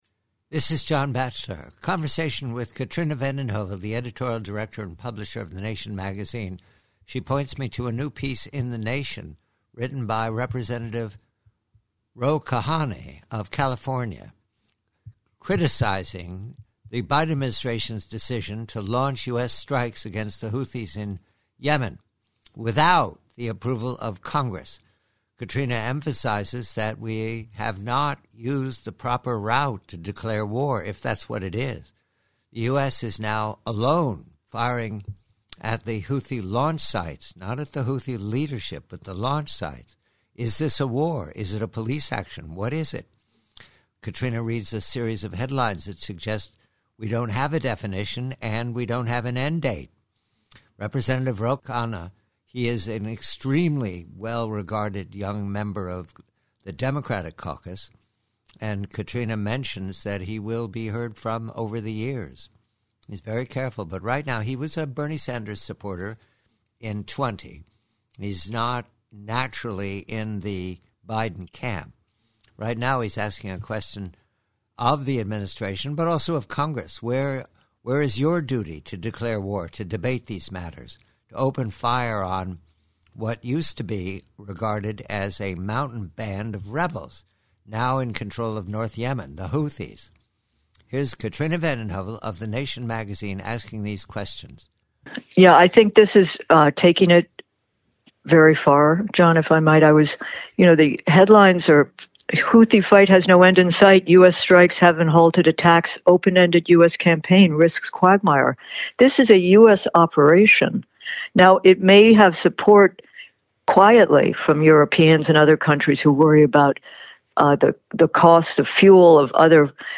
PREVIEW: Excerpt from a conversation with the Nation publisher Katrina Vanden Heuvel re the absence of congressional debate or permission to open fire on the Houthis of North Yemen.